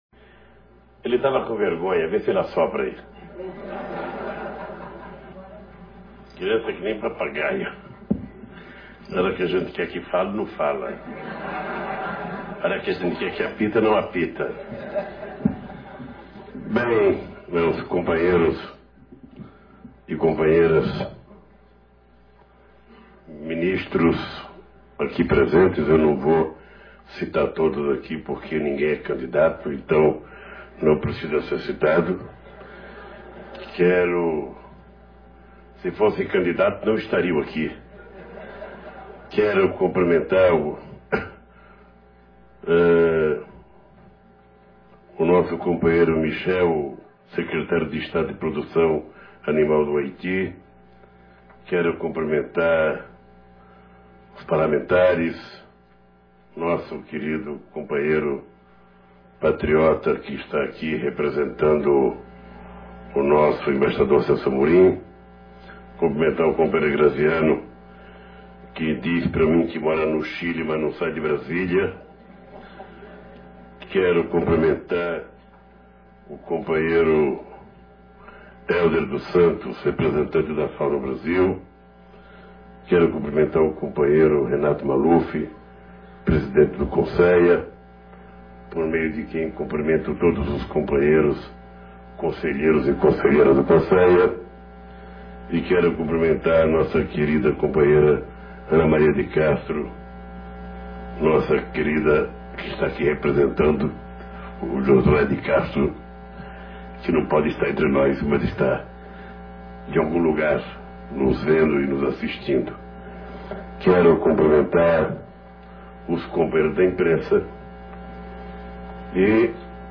Plenária Consea